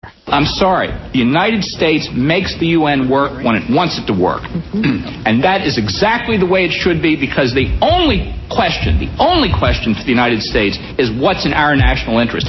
excerpt from his panel speech to the Federalist Society, 1994